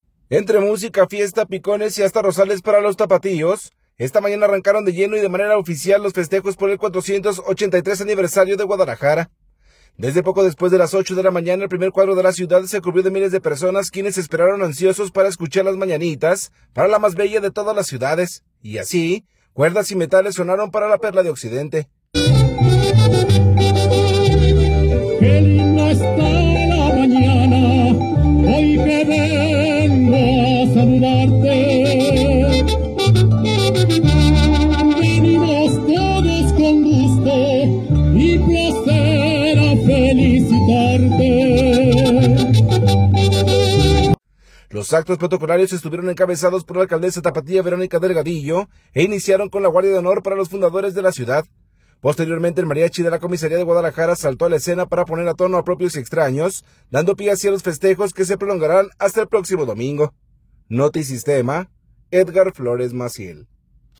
Desde poco después de las 8 de la mañana el primer cuadro de la ciudad se cubrió de miles de personas quienes esperaron ansiosos para escuchar Las Mañanitas para la más bella de todas las ciudades, y así, cuerdas y metales sonaron para la Perla de occidente. Los actos protocolarios estuvieron encabezados por la alcaldesa tapatía, Verónica Delgadillo, e iniciaron con la Guardia de Honor para los fundadores de la ciudad.